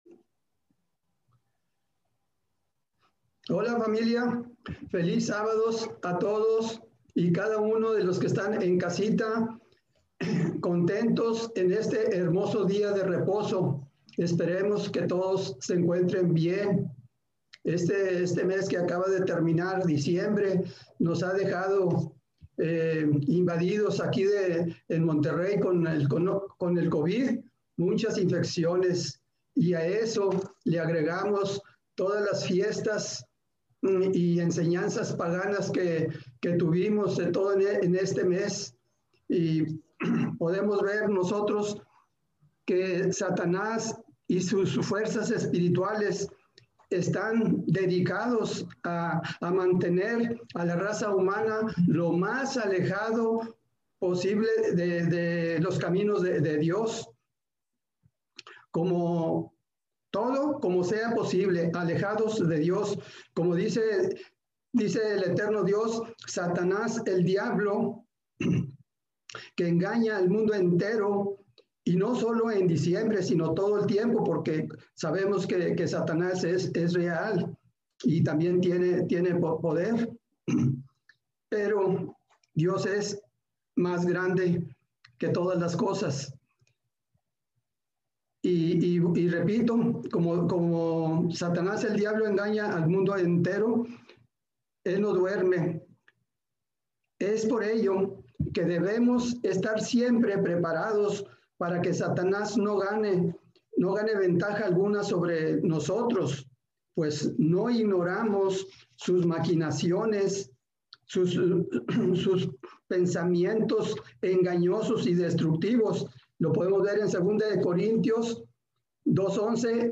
Sermones
Given in Monterrey